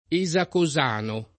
esacosano
esacosano [ e @ ako @# no ]